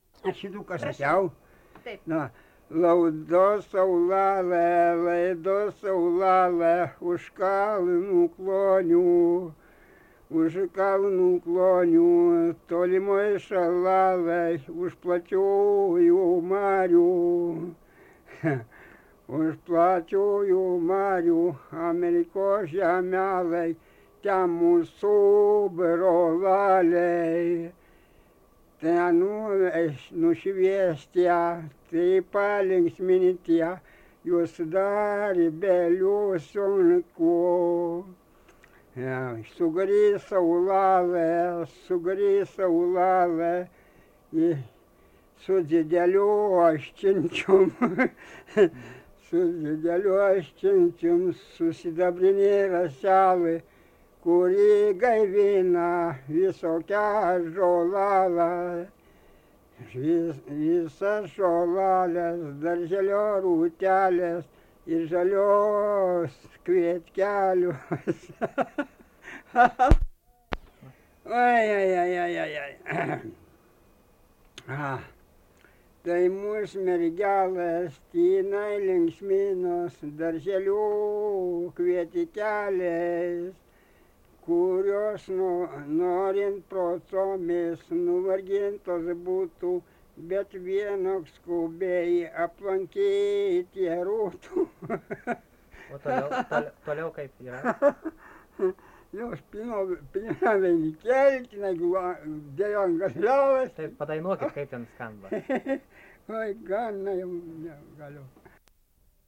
daina, vestuvių